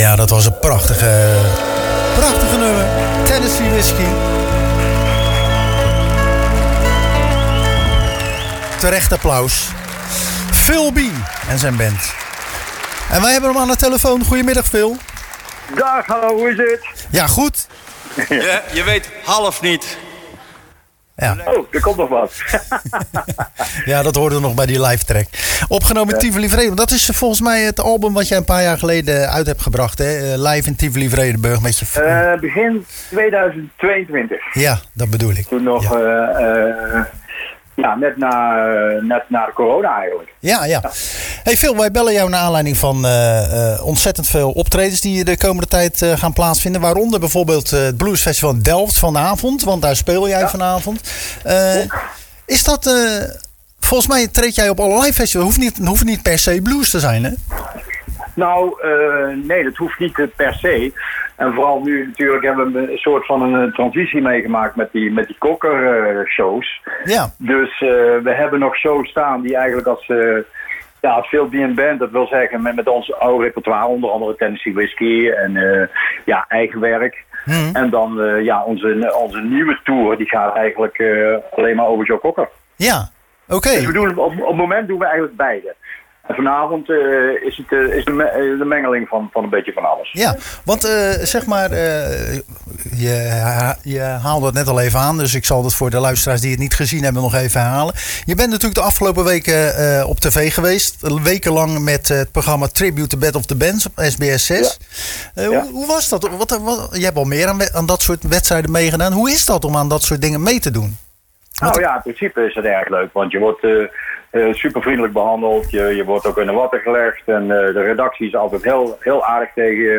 Tijdens het wekelijkse programma Zwaardvis spraken we zanger Phil Bee over o.a. zijn project Phil Bee Cockerband waarmee hij in de huid kruipt van ��n van zijn idolen 'Joe Cocker'.�Hij deed in het verleden mee aan de TV-programma's 'We Want More' en 'The Voice Senior' die hij zelfs won.